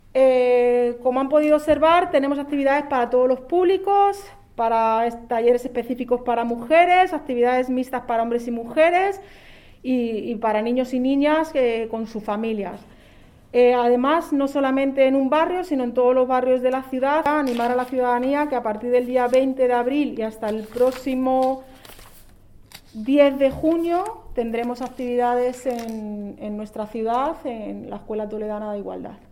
Ana Abellán ha compartido en rueda de prensa la programación que comienza este miércoles con una serie de charlas y talleres en el Centro Social de Buenavista bajo el título ‘Autocuidados y bienestar en la menopausia’.
AUDIOS. Ana Abellán, concejala de Igualdad